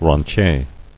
[ren·tier]